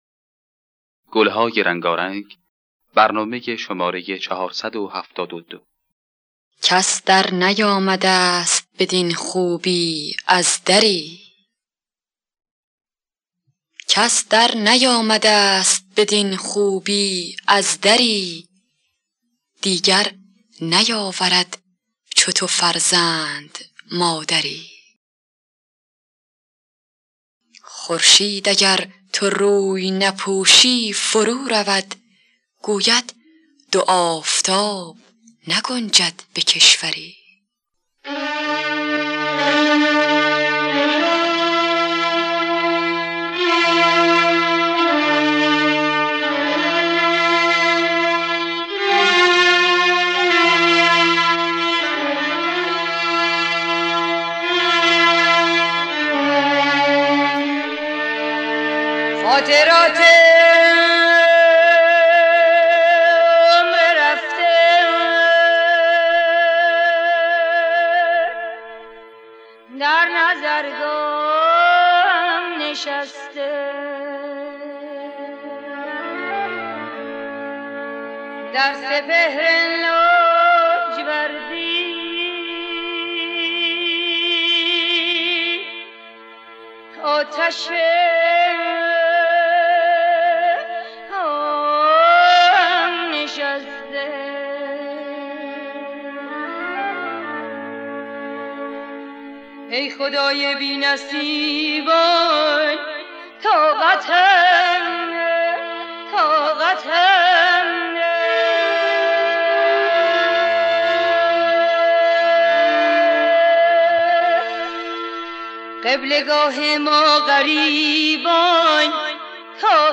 دانلود گلهای رنگارنگ ۴۷۲ با صدای مرضیه، محمدرضا شجریان در دستگاه همایون.